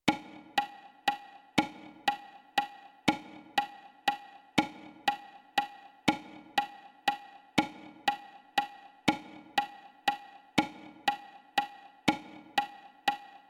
Ritmo ternario: acento cada tres pulsos. Tempo forte, tempo débil tempo débil.
ritmo_ternario.mp3